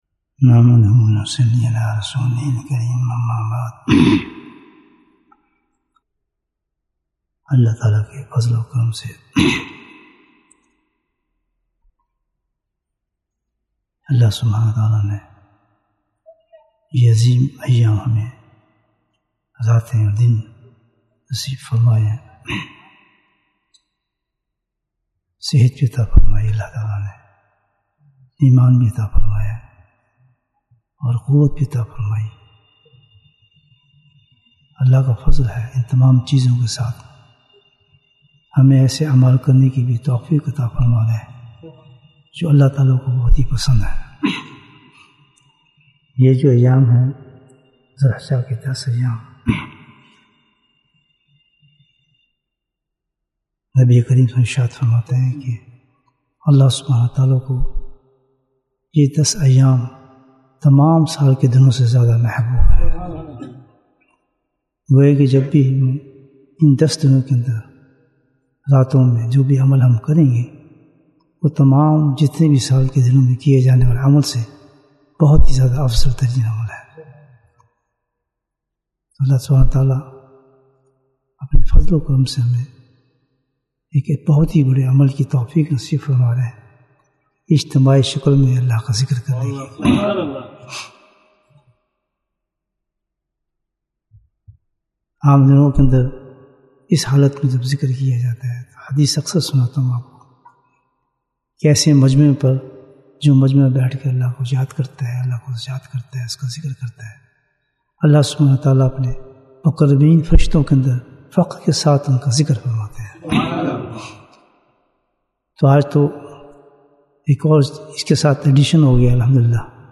Bayan, 4 minutes